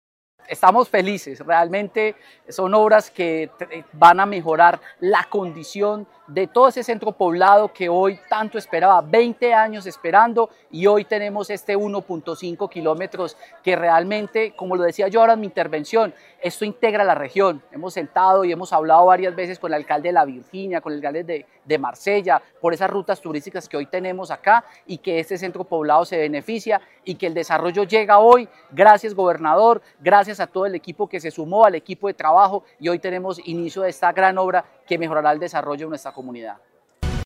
Fabio Andrés Ramírez Giraldo, alcalde de Belalcázar.